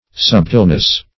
Sub"tile*ness, n.